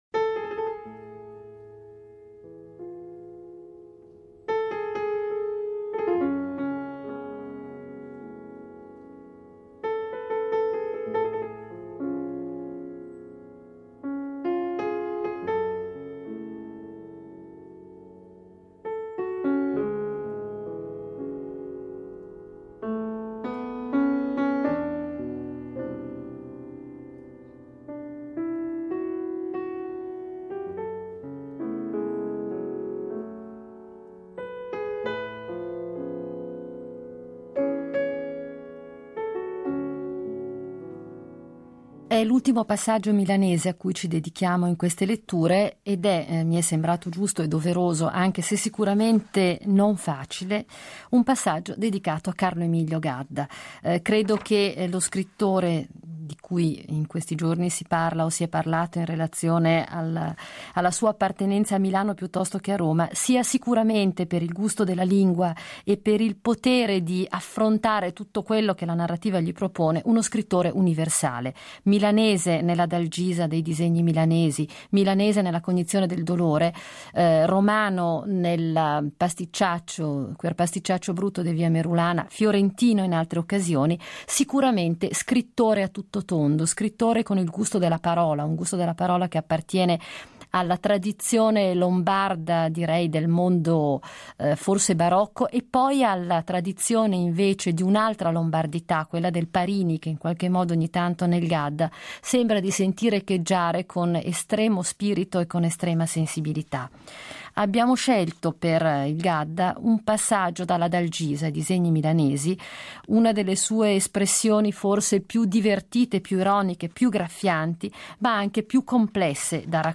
Marta Morazzoni legge e commenta Gadda